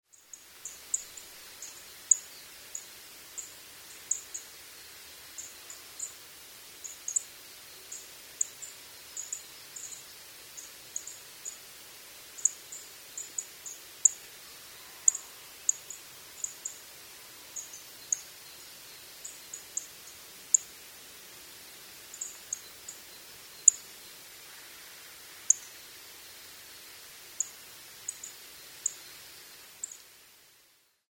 kashiradaka_c1.mp3